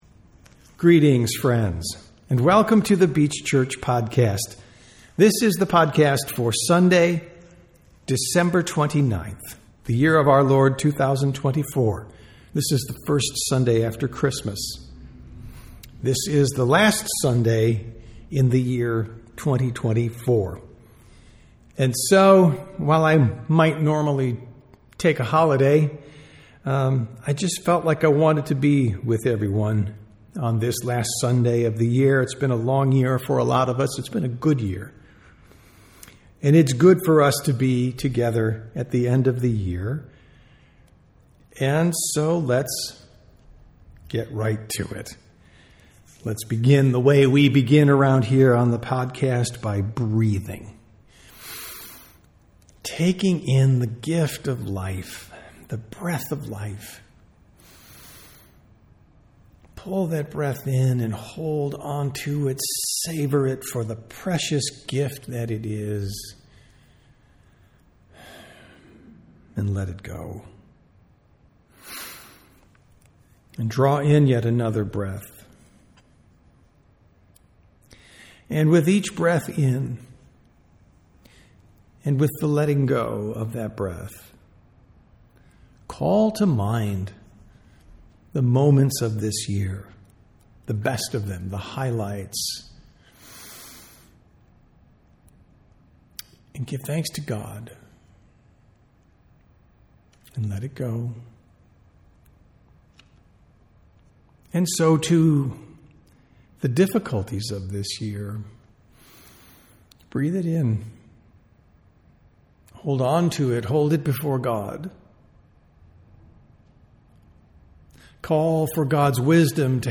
Sermons | The Beach Church
Sunday Worship - December 29, 2024